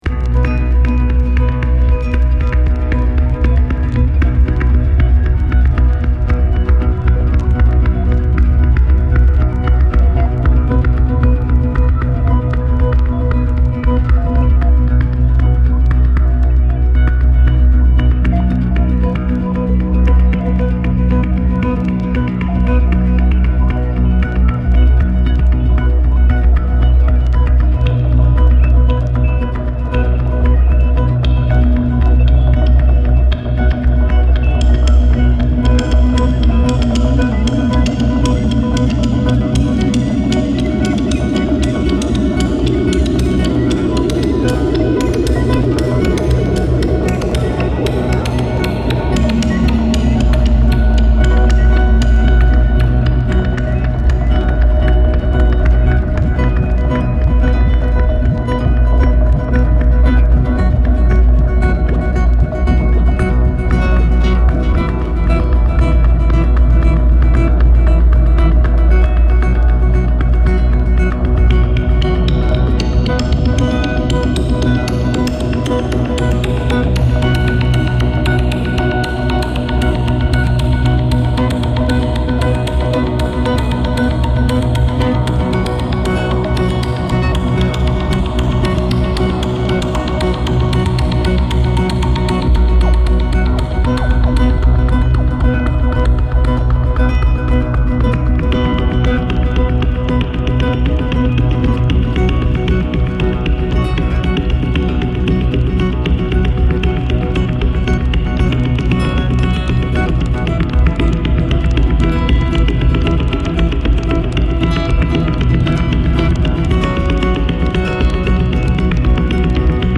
ヒプノティックにじわじわと浸食する